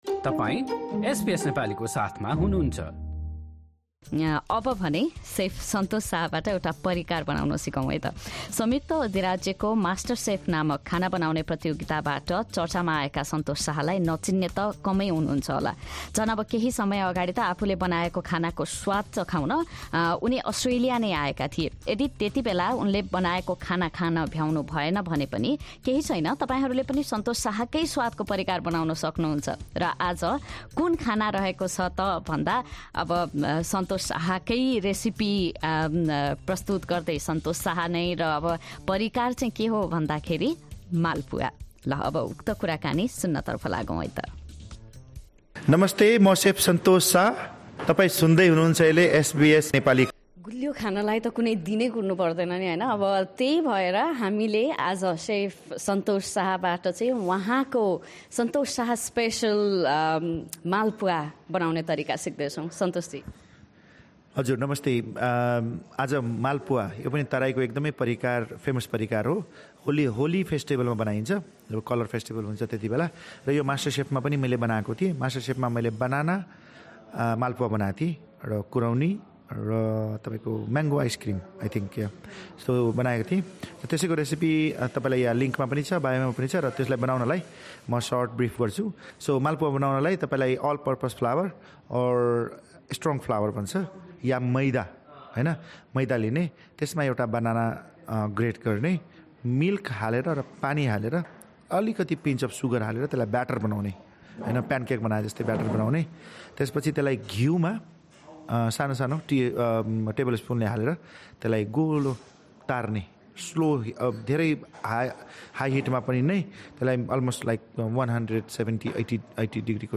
मालपुवा र राबरी कसरी बनाउने भन्ने बारे भने सन्तोष साहसँग गरिएको कुराकानी सुन्नुहोस्।